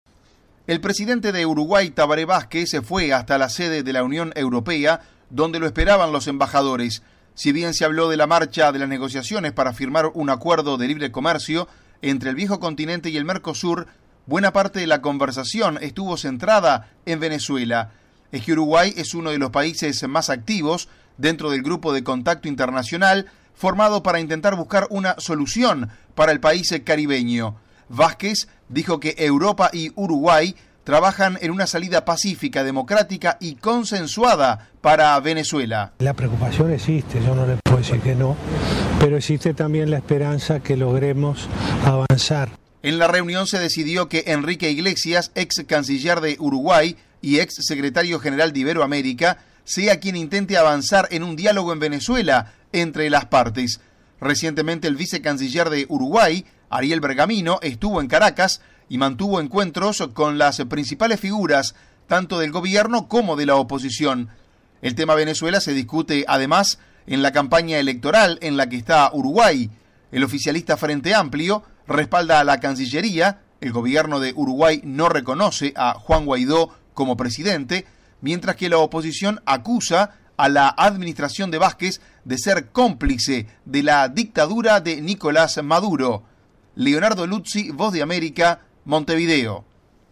VOA: Informe desde Uruguay